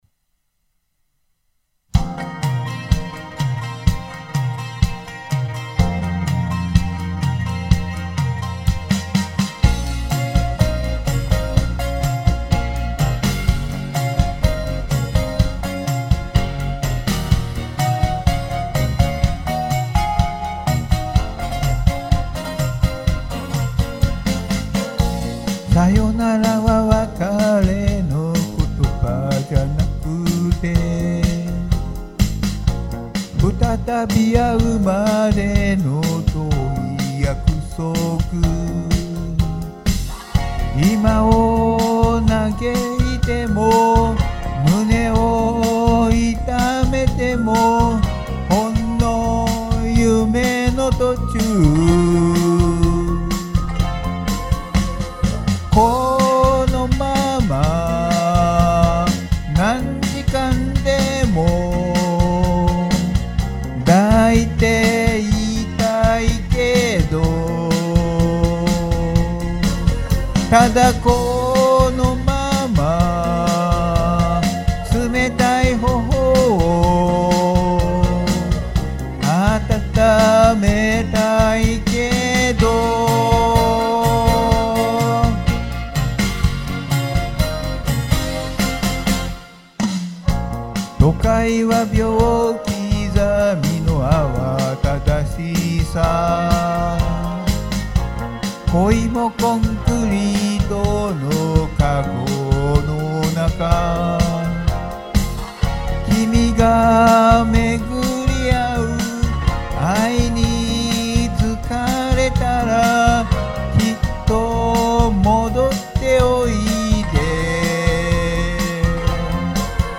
オンマイク／カラオケ用マイクロフォン使用／エコーなし